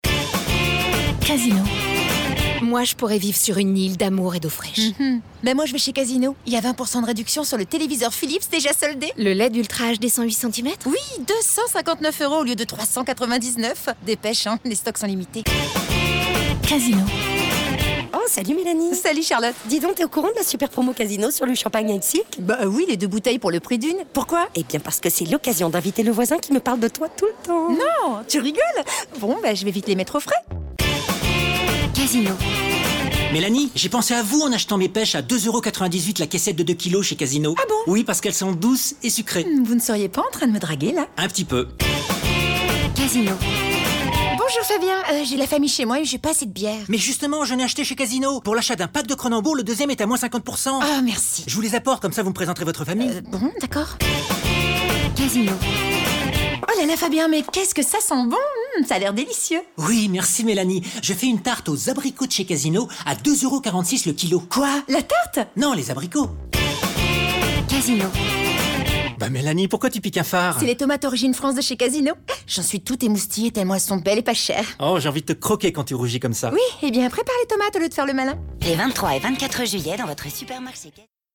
enjouée